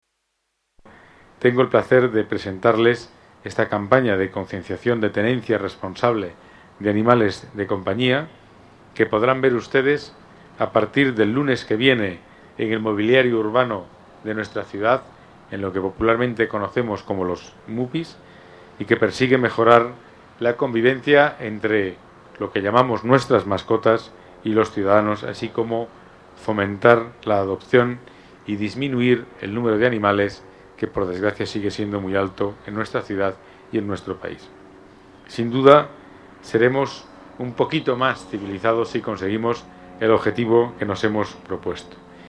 Nueva ventana:Pedro Calvo explica los objetivos de esta campaña:fomentar la adopción y disminuir el abandono